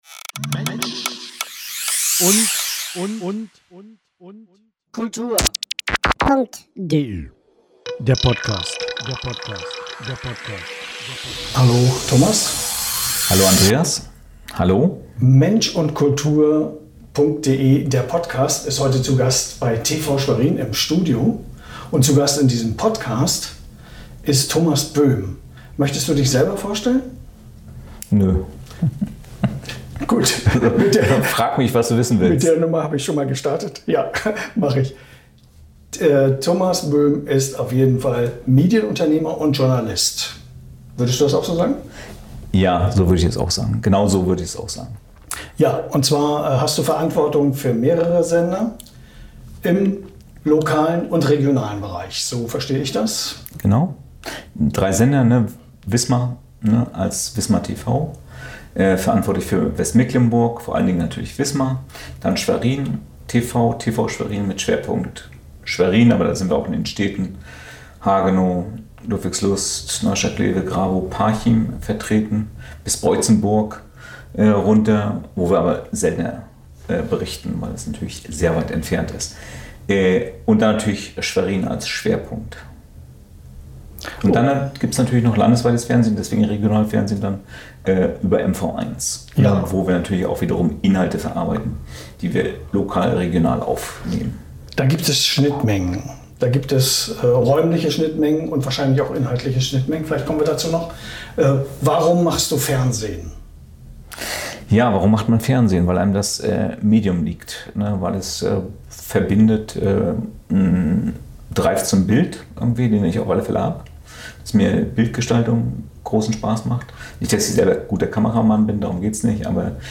Was geht im Lokaljournalismus mit Fernseh- und Videoformaten? Welche Rahmenbedingungen werden gebraucht, um professionelles Fernsehen auch im eigenen Wohnumfeld zu erleben und damit zu interagieren? Das Interview schließt thematisch an die Folge 9 (S1F9) "Lokaljournalismus heute.